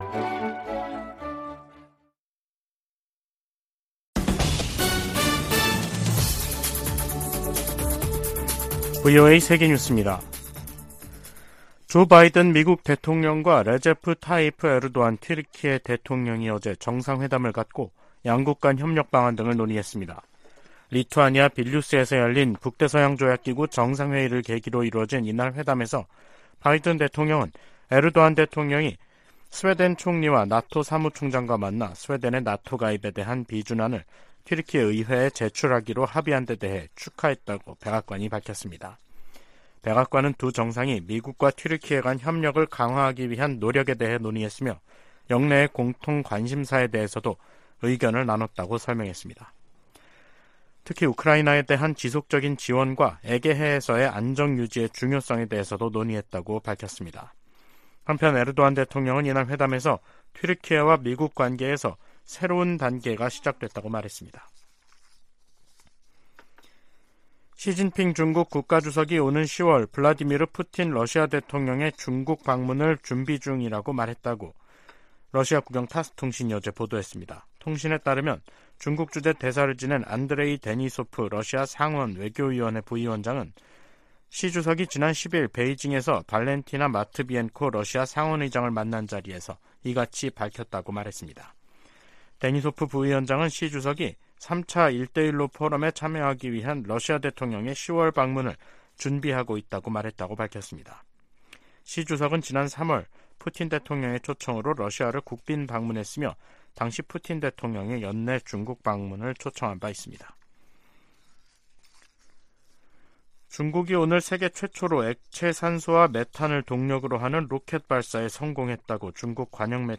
VOA 한국어 간판 뉴스 프로그램 '뉴스 투데이', 2023년 7월 12일 2부 방송입니다. 한국 정부가 북한 정권의 대륙간탄도미사일(ICBM) 발사를 규탄하며 불법 행위에는 대가가 따를 것이라고 경고했습니다. 미국과 한국, 일본의 북 핵 수석대표들도 북한의 ICBM 발사는 유엔 안보리 결의를 위반하는 심각한 도발로, 어떤 이유로도 정당화할 수 없다고 비판했습니다. 미 국무부는 미군 정찰기가 불법 비행했다는 북한 정권의 주장은 근거가 없다고 지적했습니다.